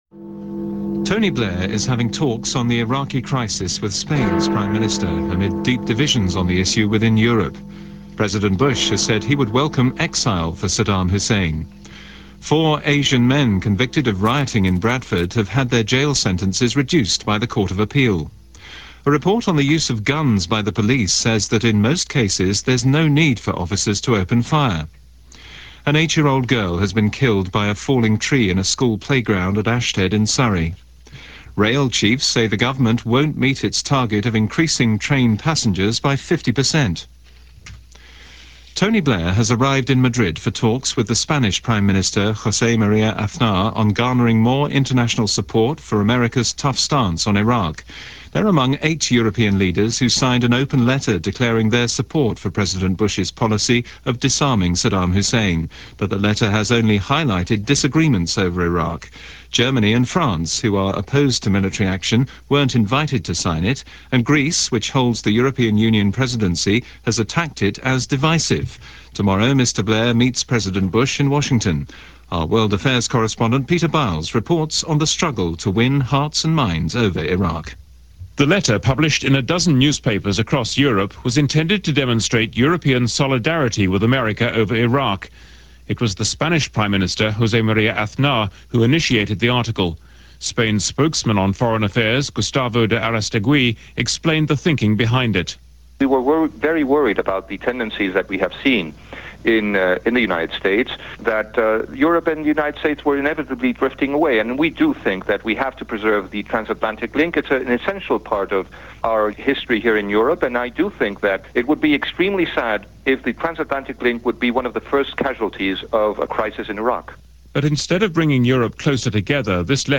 - news from BBC Radio 4 Newshour - lead-up to the Iraq War.